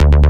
FK092BASS1-L.wav